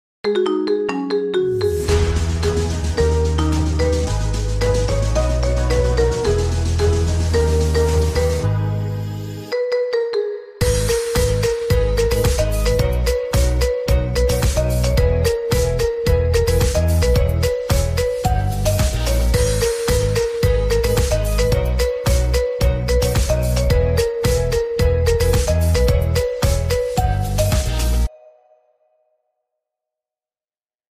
Marimba Remix